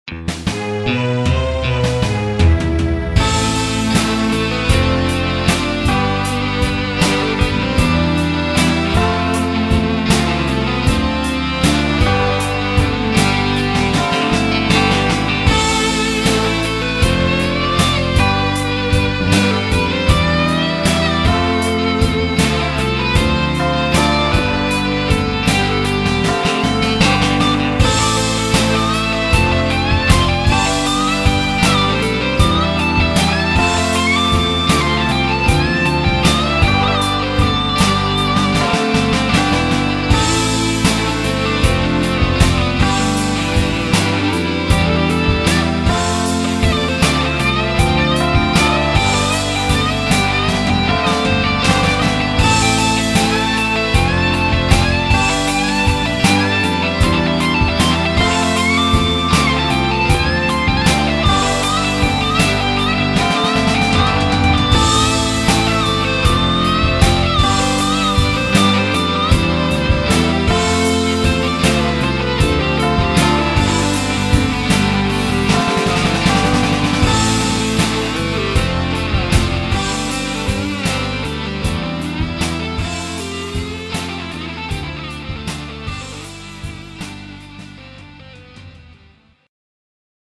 Guitare Electrique